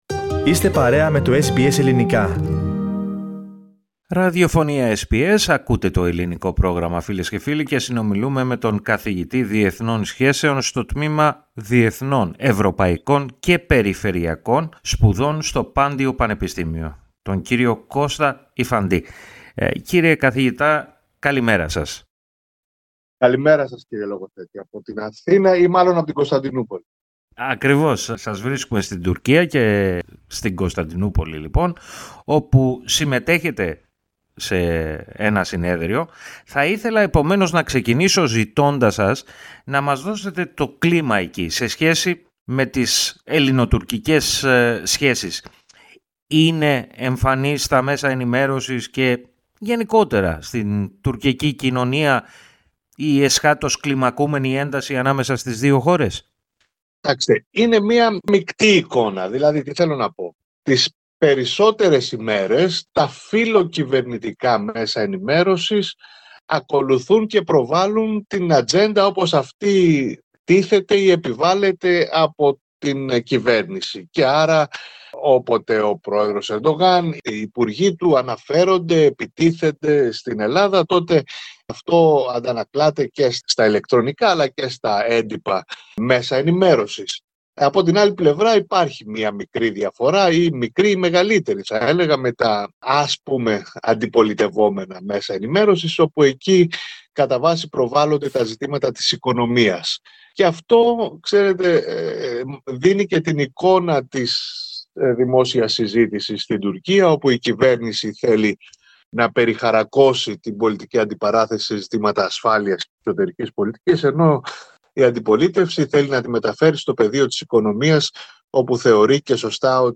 Βρισκόμενος στην Κωνσταντινούπολη, απ’ όπου παραχώρησε τη συνέντευξη, περιέγραψε και το κλίμα που επικρατεί στα τουρκικά μέσα ενημέρωσης, και την τουρκική κοινωνία εν γένει σε σχέση με τις ελληνοτουρκικές σχέσεις. READ MORE Πώς αποτιμά η Άγκυρα την επίσκεψη Μητσοτάκη στις ΗΠΑ Σημείωσε ότι για πρώτη φορά, εδώ και πάρα πολλά χρόνια που παρακολουθεί τα ελληνοτουρκικά, διαπιστώνει τόσο τοξική ρητορική από Τούρκους αξιωματούχους. Επιπλέον, μίλησε για το ενδεχόμενο εκδήλωσης ενός θερμού επεισοδίου ανάμεσα στις δύο χώρες.
READ MORE ‘Πρωτοφανών διαστάσεων ήττα της Ρωσίας η εισβολή στην Ουκρανία’ Ακούστε, όμως, ολόκληρη τη συνέντευξη, πατώντας στο σύμβολο, που βρίσκεται στην κύρια φωτογραφία.